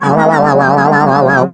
rick_die_03.wav